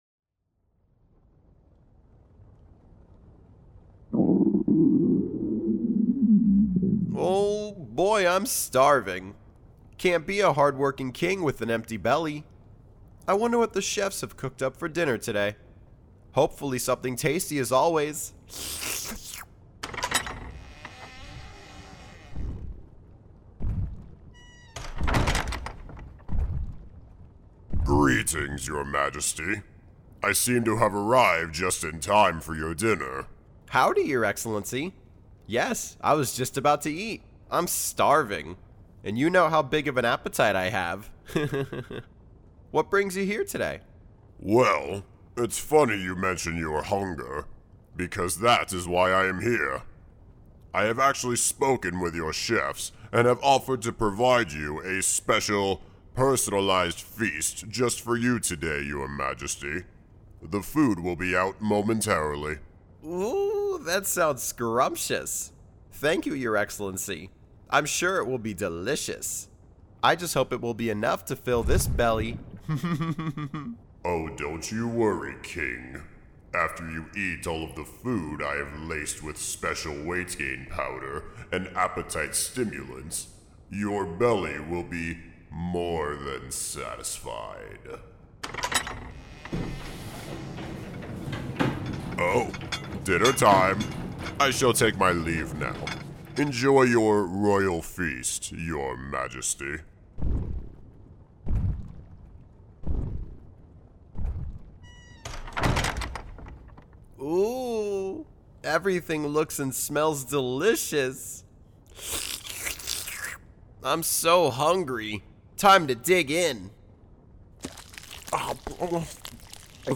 This is a compressed, lower-quality version;
*As always, this audio is better experienced with headphones*